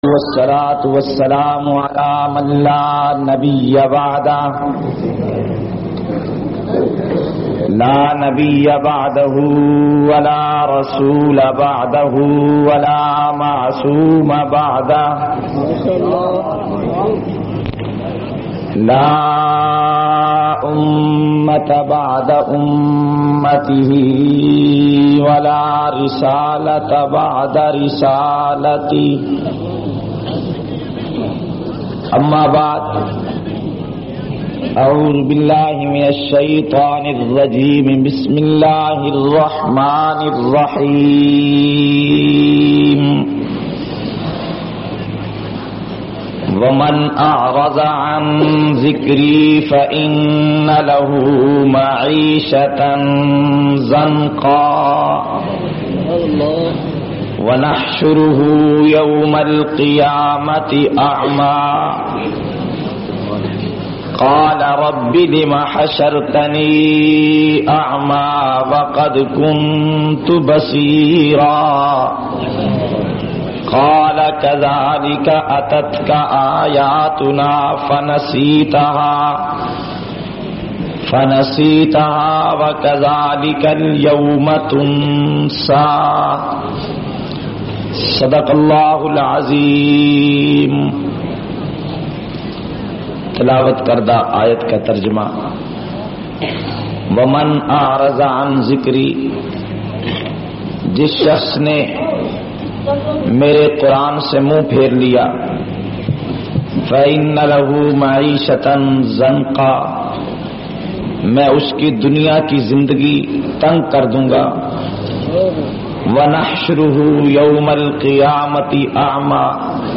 457- Azmat e Quran Conference-RawalPindi.mp3